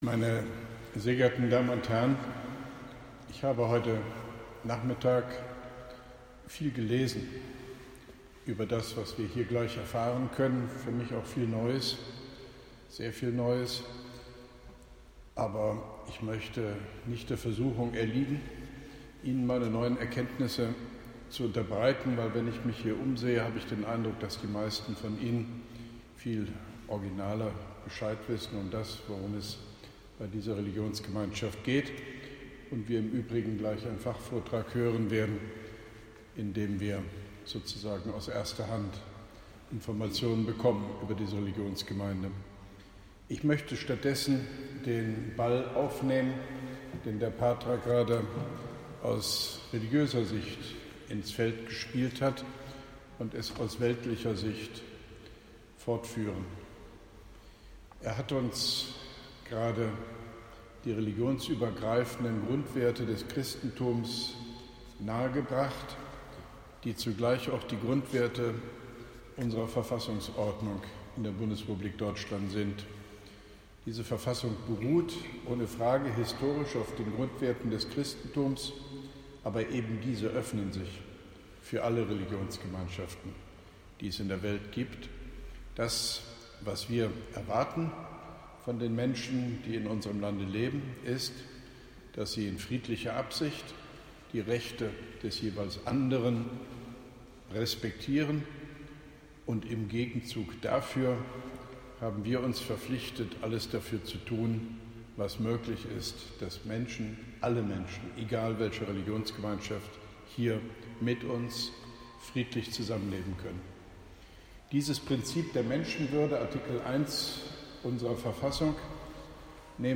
OB Hofmann-Göttig begrüßt in der Koblen-zer Citykirche das Publikum zum Fachvor-trag über verfolgte kurdische Religionsge-meinschaft der Eziden
Grußwort von OB Hofmann-Göttig bei der Ausstellungseröffnung “Eziden und das Ezidentum” – Geschichte und Gegenwart einer vom Untergang bedrohten Religion”, Koblenz 13.05.2017